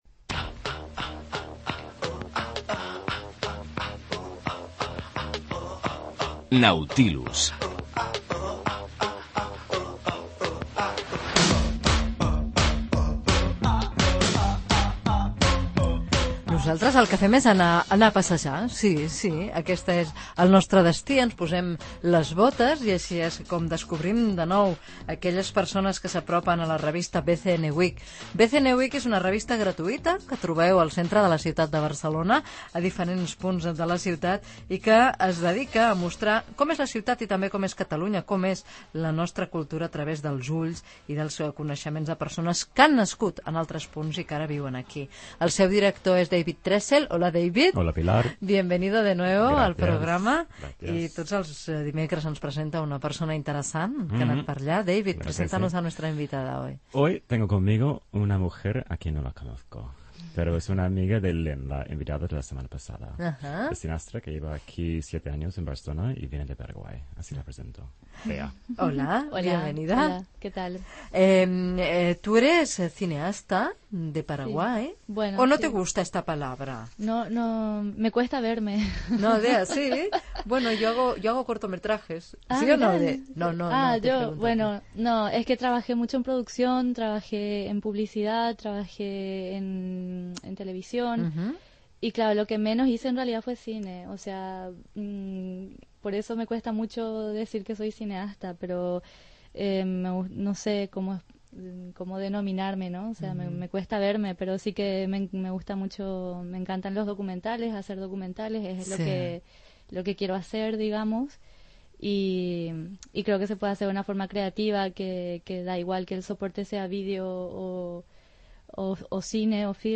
Indicatiu del programa i de l'emissora (musical) Gènere radiofònic Cultura